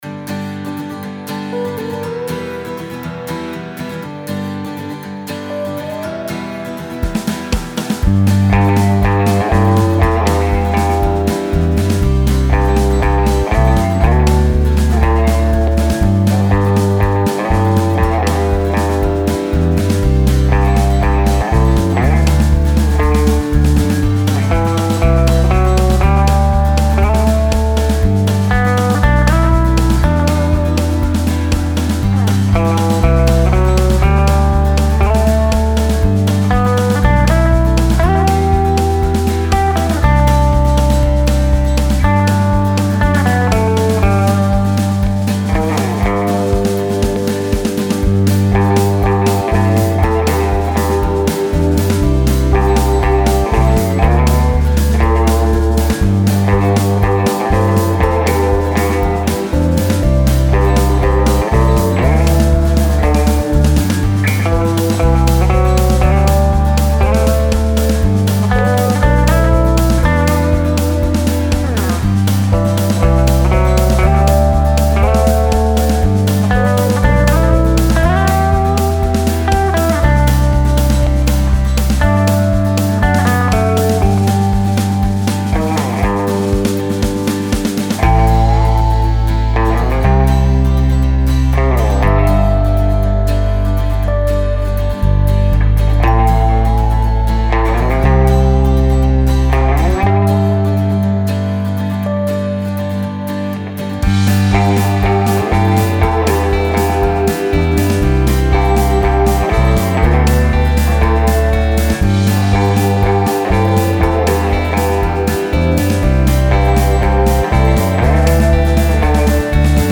Style Style Country
Mood Mood Bright, Uplifting
BPM BPM 120